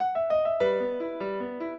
piano
minuet9-4.wav